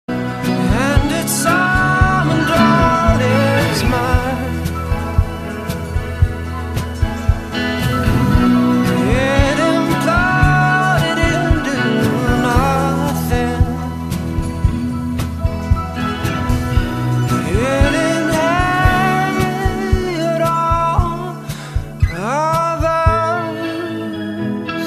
M4R铃声, MP3铃声, 欧美歌曲 101 首发日期：2018-05-14 09:33 星期一